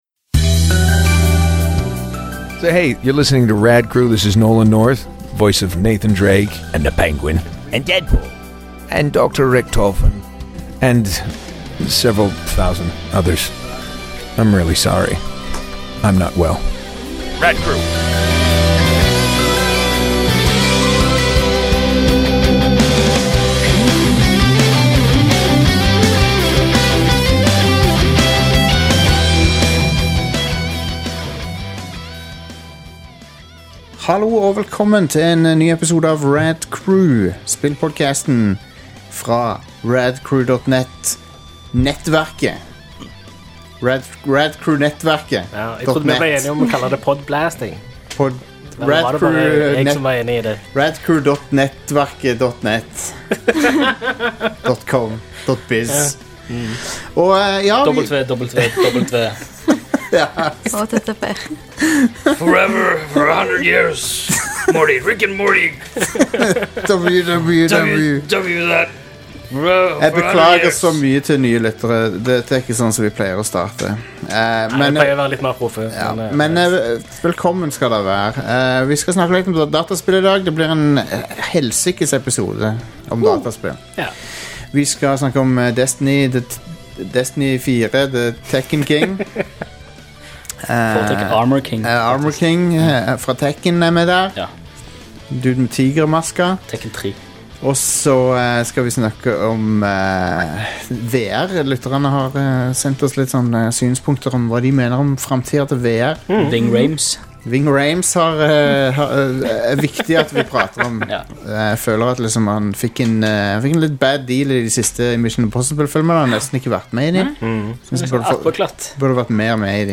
intervjuer voice actor-legenden Nolan North